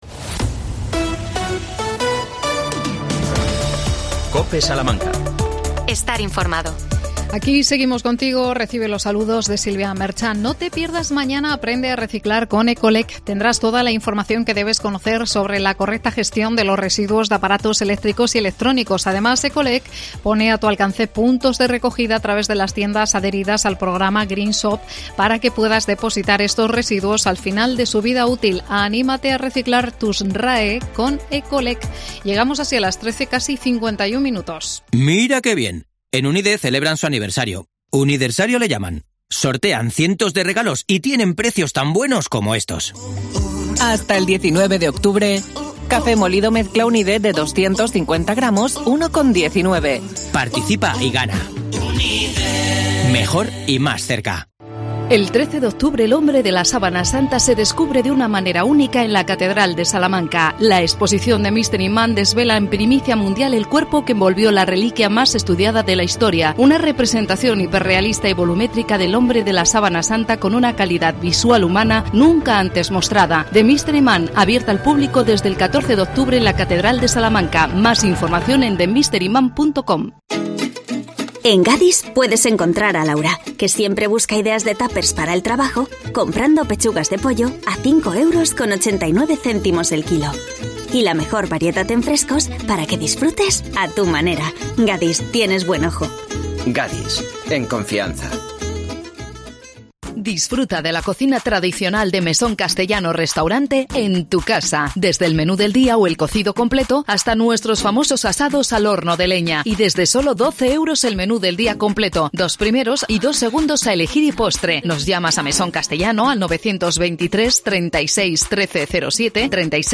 AUDIO: Arranca la vacunación contra la gripe. Entrevistamos al experto